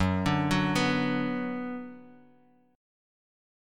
Gbsus4#5 chord